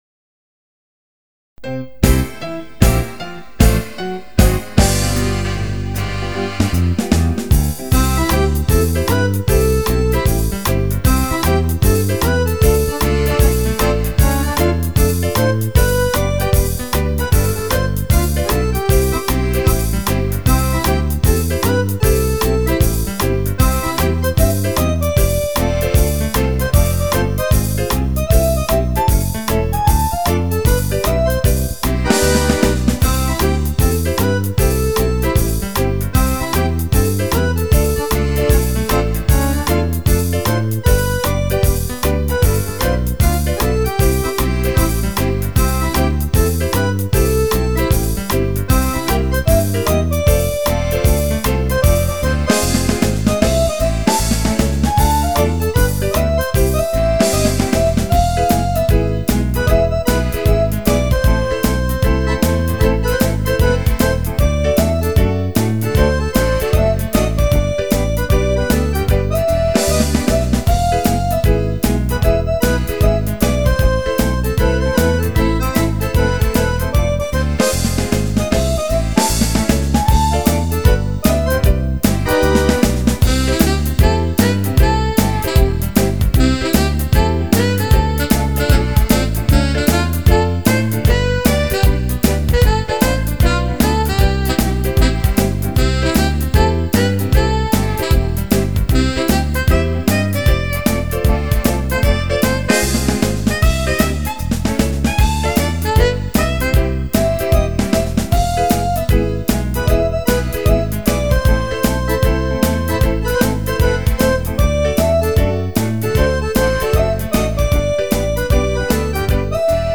Canzoni e musiche da ballo
fox